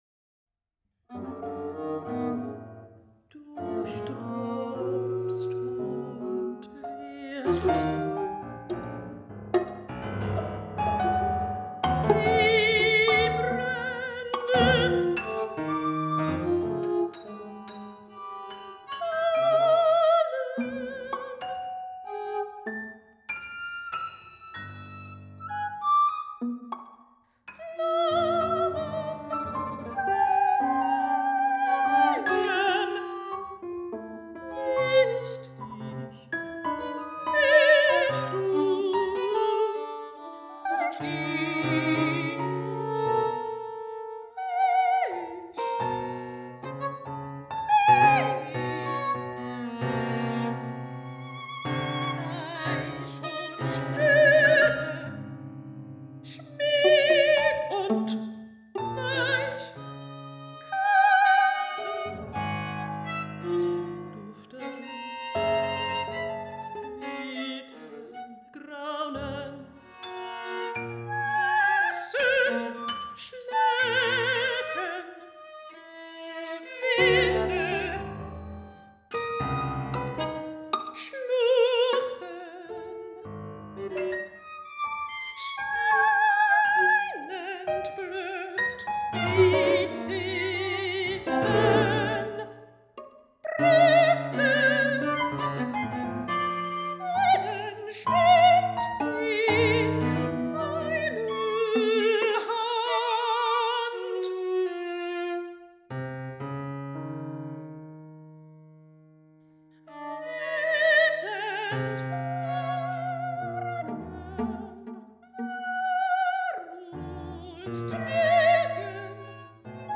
voice
viola
piano